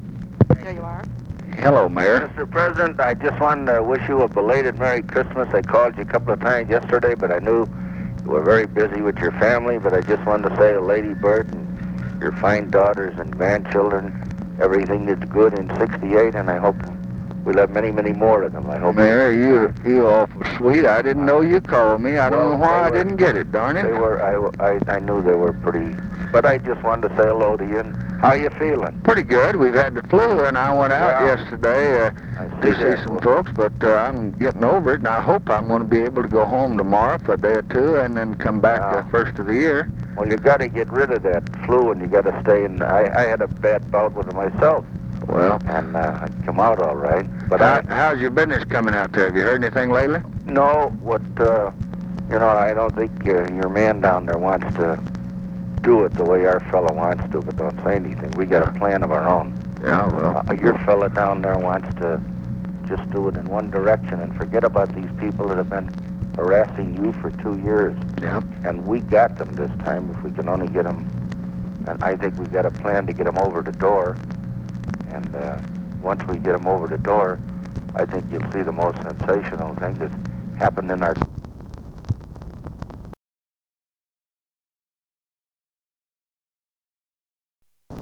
Conversation with RICHARD DALEY, December 26, 1968
Secret White House Tapes